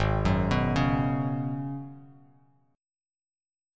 G#m11 chord